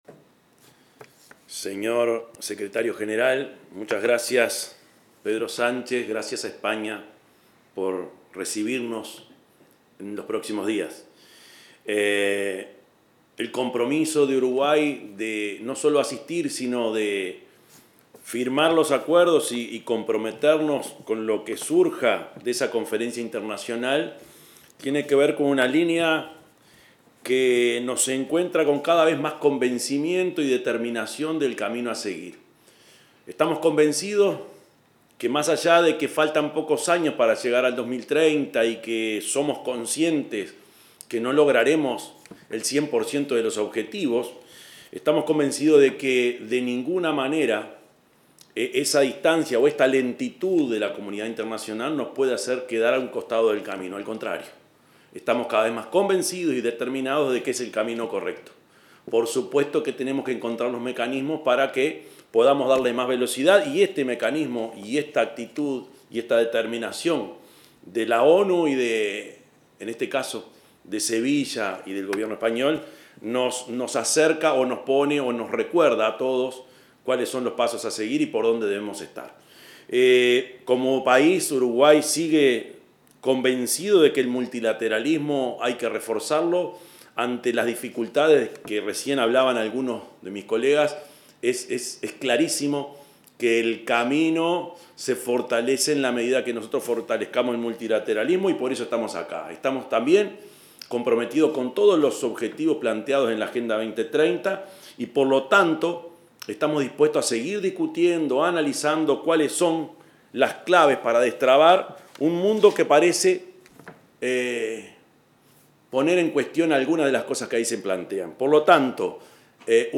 Palabras del presidente Orsi en reunión previa a la 4.ª Conferencia sobre Financiamiento para el Desarrollo Sostenible
Palabras del presidente Orsi en reunión previa a la 4.ª Conferencia sobre Financiamiento para el Desarrollo Sostenible 03/06/2025 Compartir Facebook X Copiar enlace WhatsApp LinkedIn El presidente de la República, profesor Yamandú Orsi, participó en una reunión virtual convocada por la Organización de las Naciones Unidas, como instancia previa a la 4.ª Conferencia sobre Financiamiento para el Desarrollo Sostenible.